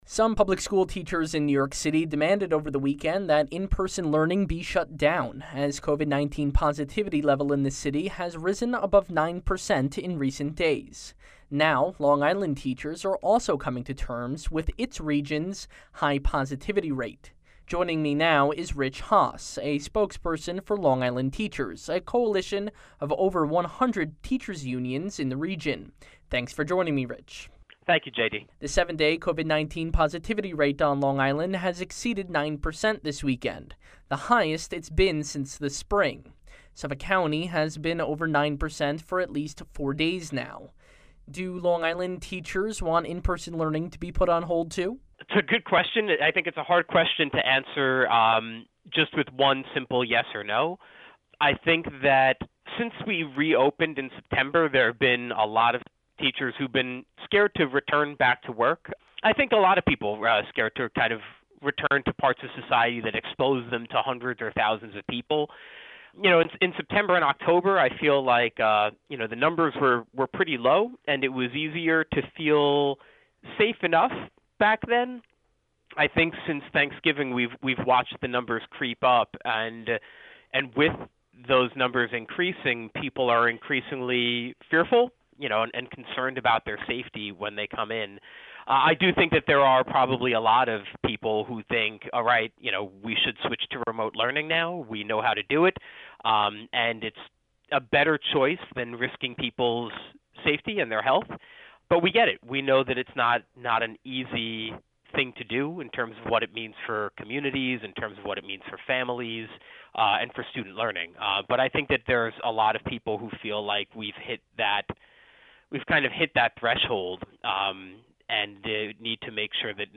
WSHU/NPR: All Things Considered Interview on LI Infection Rates Exceeding 9%